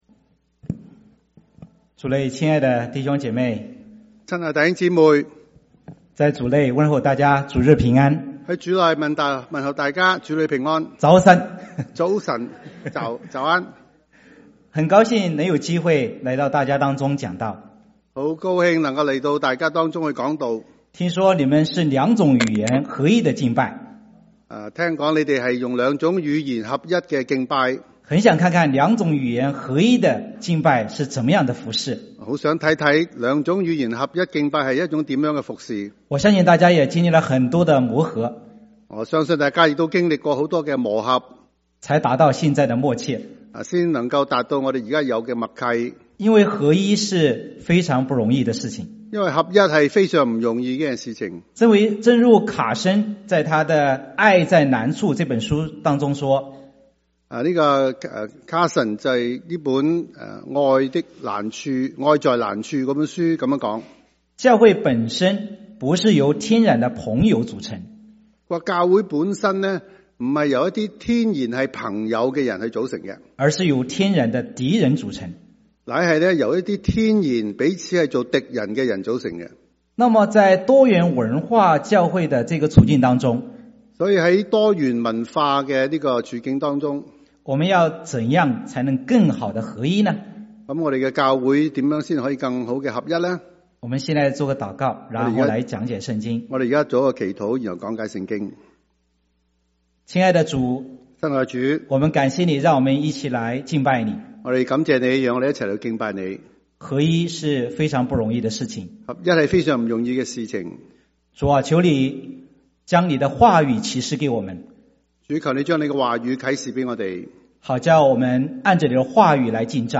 3/23/2025 國粵語聯合主日崇拜: 「牧養教會合一的要務」